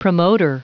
Prononciation du mot promoter en anglais (fichier audio)